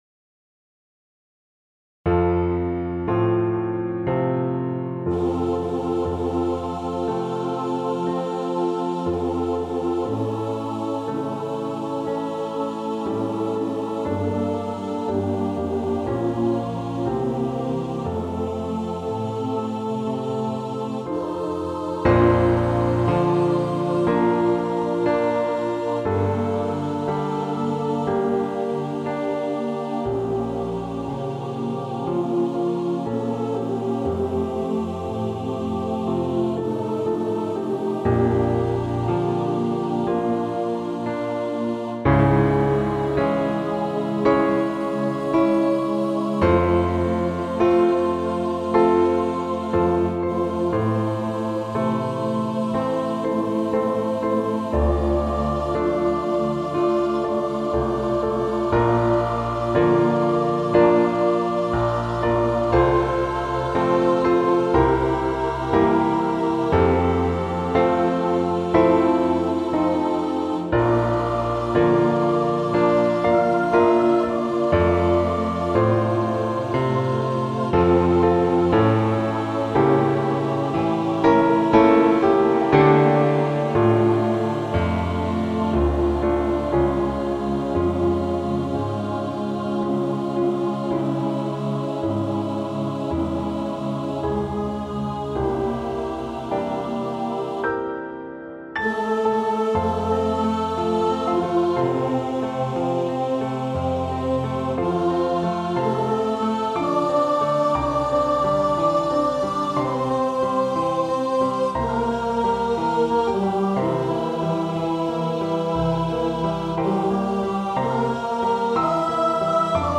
• Music Type: Choral
• Voicing: SATB
• Accompaniment: Piano
Written in a contemporary style